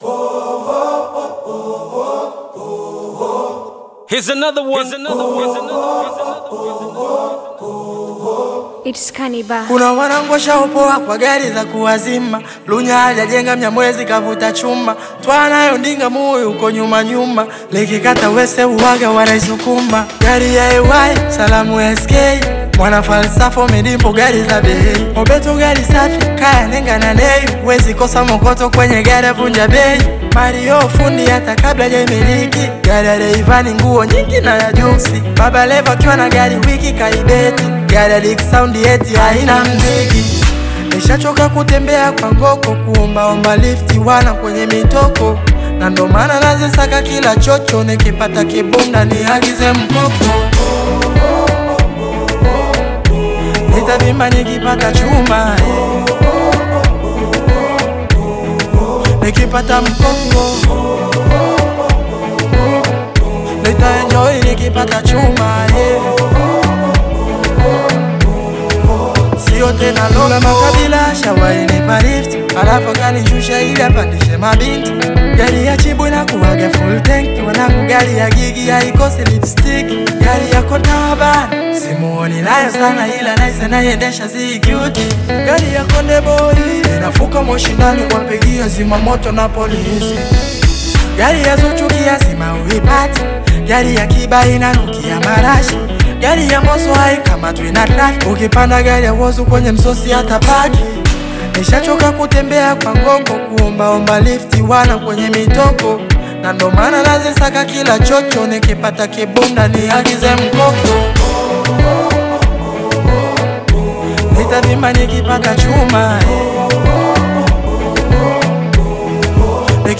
Tanzanian Bongo Flava
Bongo Flava song